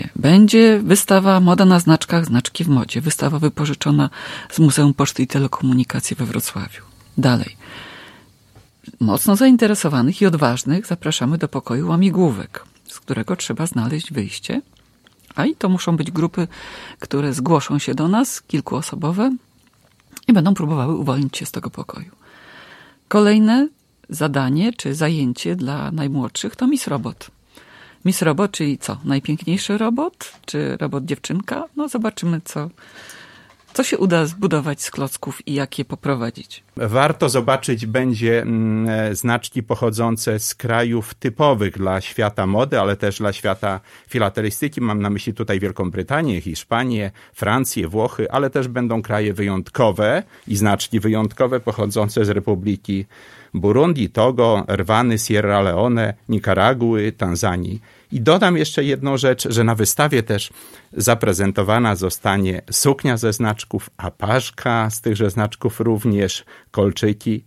Gry, konkursy i pokazy filmów dla dzieci, spotkania z podróżnikami, pokaz tańca oraz wystawę filatelistyczną. O szczegółach opowiadali w piątek (02.06) w Radiu 5